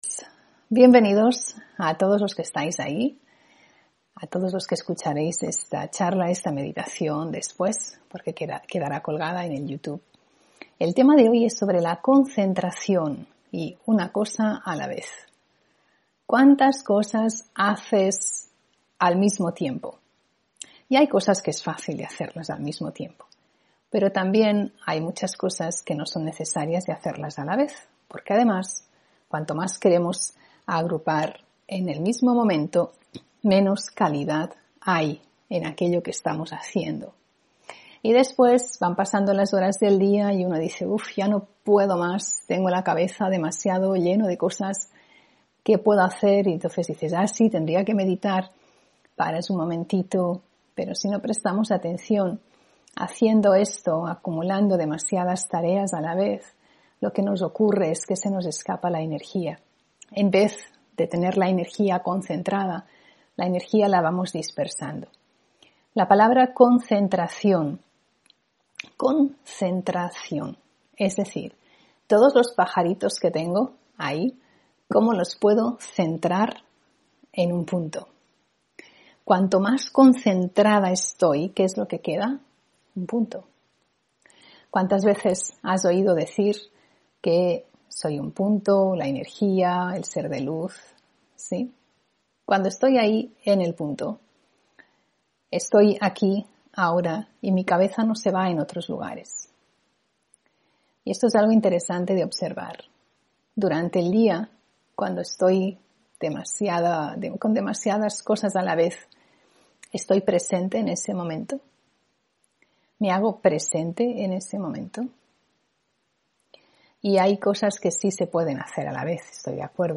Meditación y conferencia: Concentración una cosa a la vez (18 Noviembre 2021)